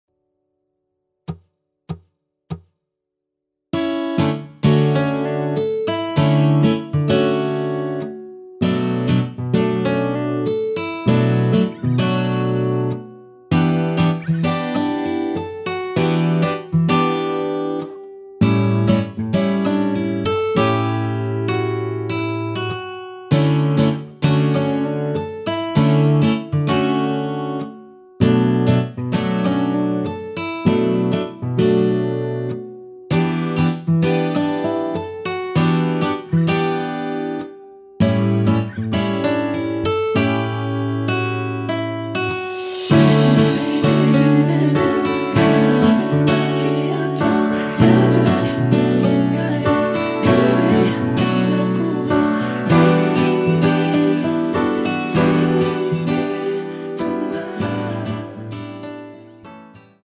◈ 곡명 옆 (-1)은 반음 내림, (+1)은 반음 올림 입니다.
앞부분30초, 뒷부분30초씩 편집해서 올려 드리고 있습니다.